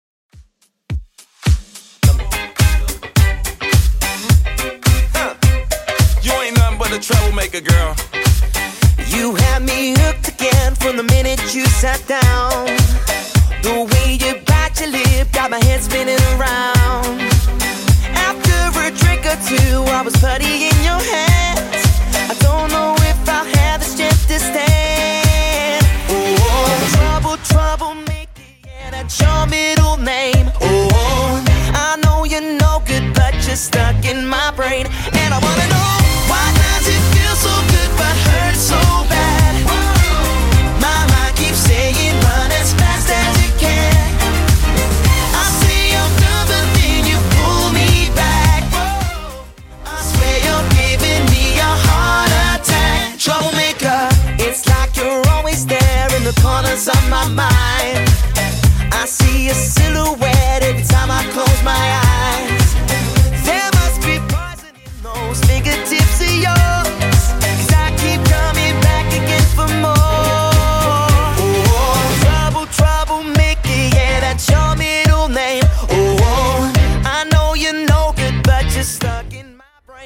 Genre: 80's
BPM: 117